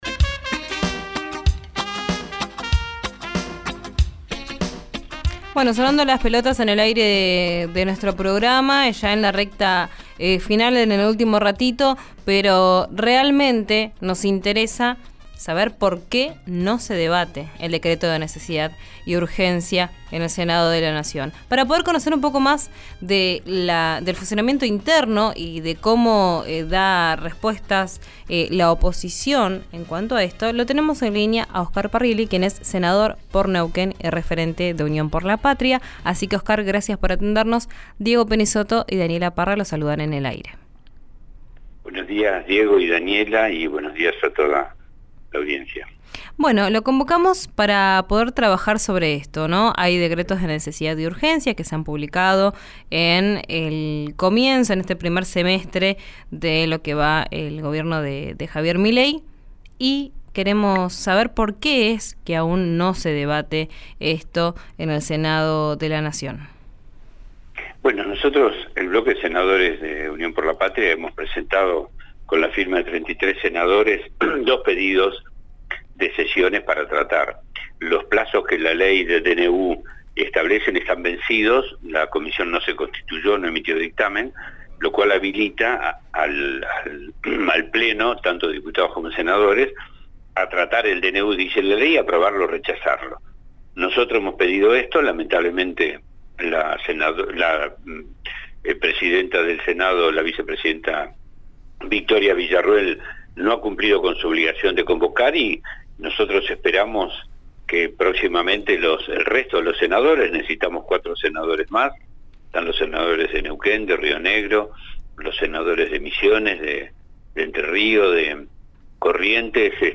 El senador por Neuquén y referente de Unión por la Patria sostuvo que el oficialismo no quiere llevarlo al recinto porque no le dan los votos. Escuchá la entrevista en RÍO NEGRO RADIO.
Escuchá al senador Oscar Parrilli en RÍO NEGRO RADIO: